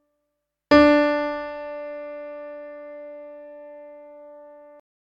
If You’re Happy: Tonic
Ex-5-tonic.mp3